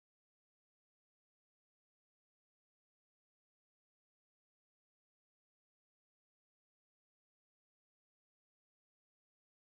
nosound.mp3